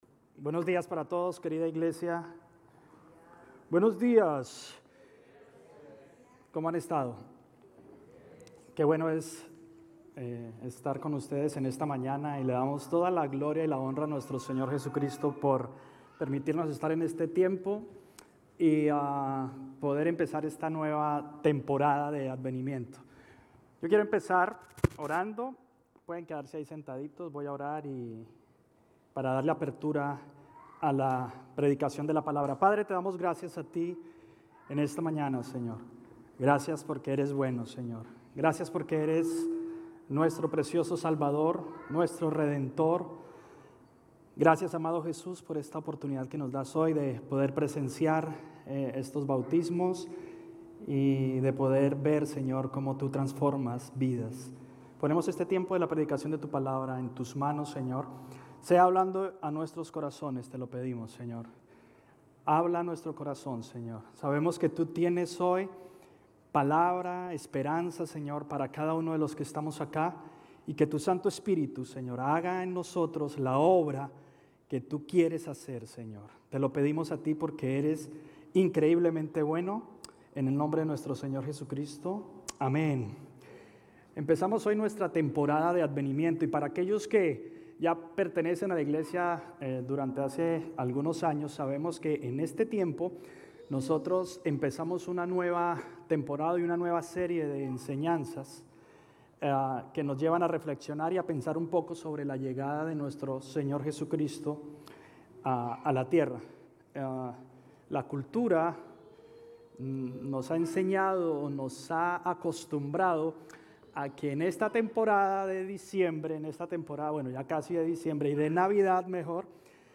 Sermones Grace Español Advenimiento - Esperanza Nov 27 2022 | 00:34:15 1x 00:00 / 00:34:15 Subscribe Share RSS Feed Share Link Embed